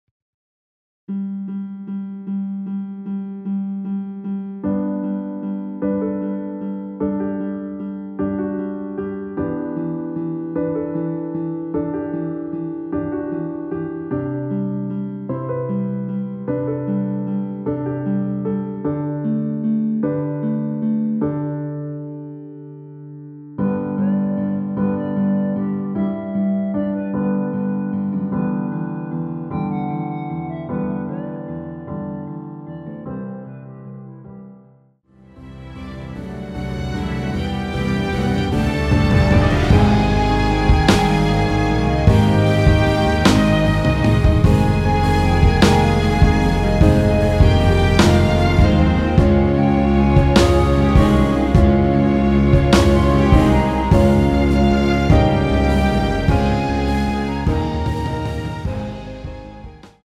원키에서(-5)내린 멜로디 포함된 MR입니다.(미리듣기 확인)
앞부분30초, 뒷부분30초씩 편집해서 올려 드리고 있습니다.
중간에 음이 끈어지고 다시 나오는 이유는